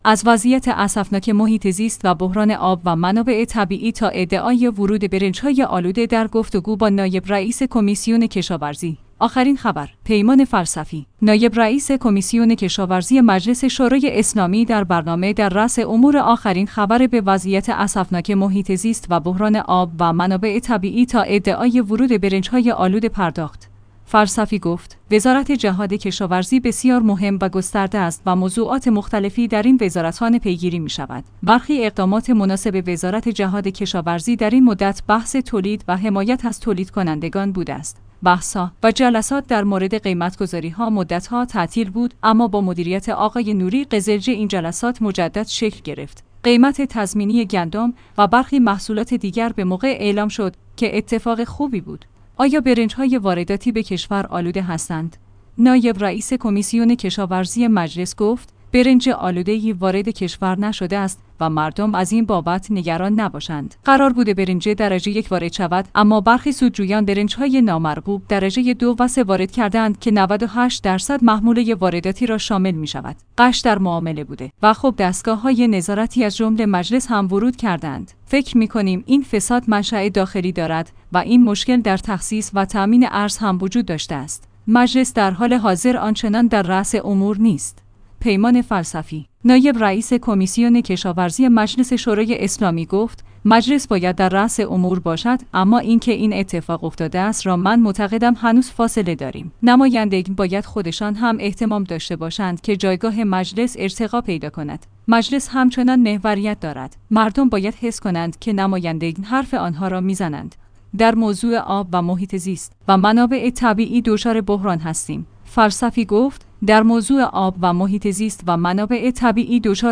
از وضعیت اسفناک محیط زیست و بحران آب و منابع طبیعی تا ادعای ورود برنج‌های آلوده در گفتگو با نایب رئیس کمیسیون کشاورزی